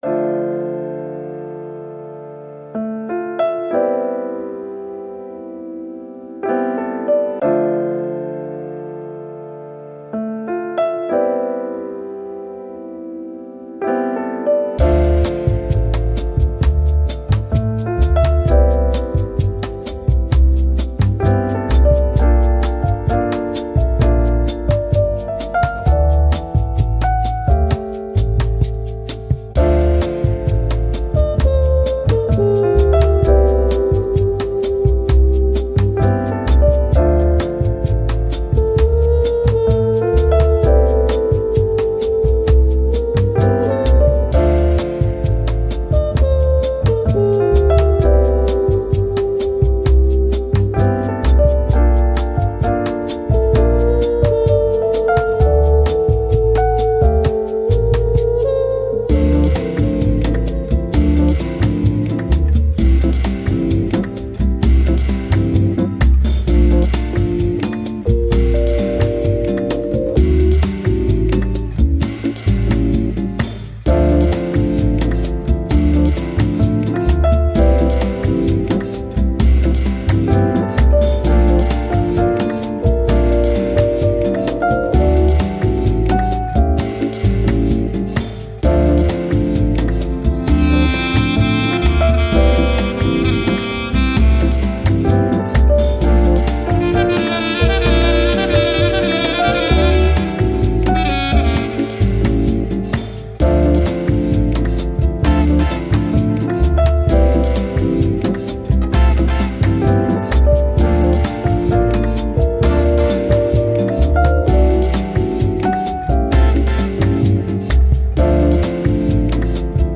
Bossa Nova, Jazz, Chill, Elegant